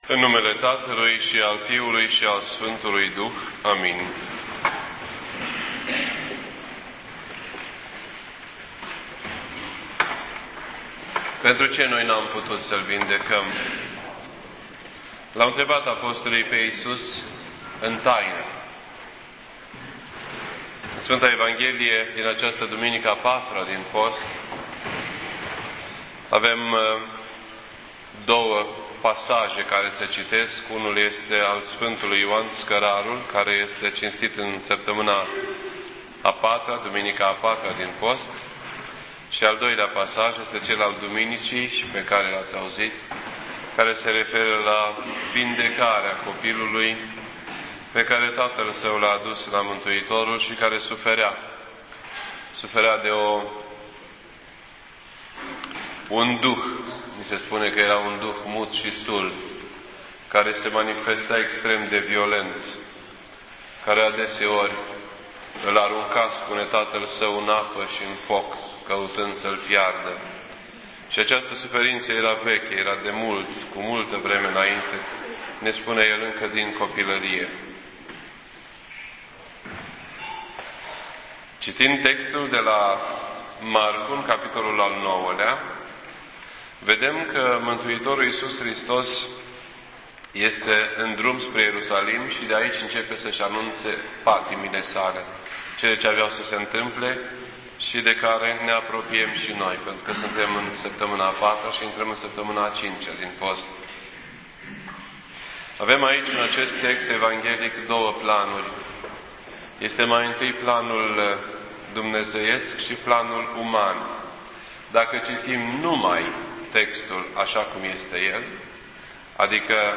This entry was posted on Sunday, April 3rd, 2011 at 9:04 PM and is filed under Predici ortodoxe in format audio.